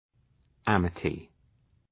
{‘æmətı}